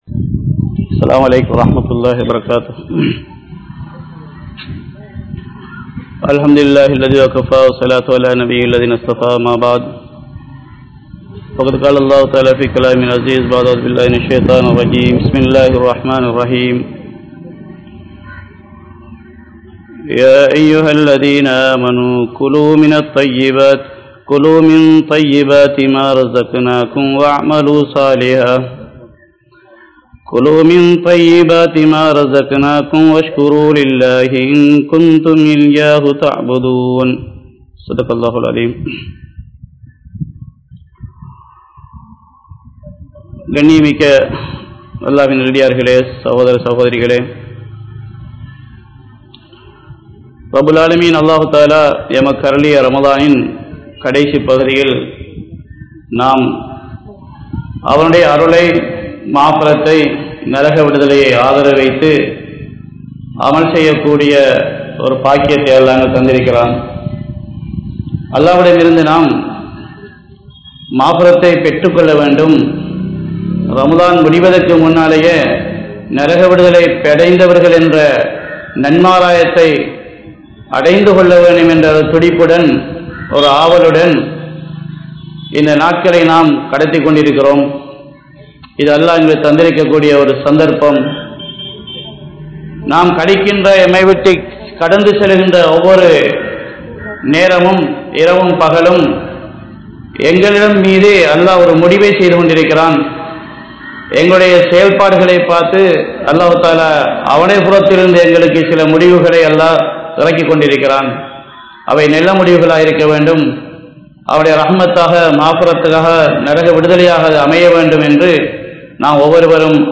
Haraaththin Vilaivuhal (ஹராத்தின் விளைவுகள்) | Audio Bayans | All Ceylon Muslim Youth Community | Addalaichenai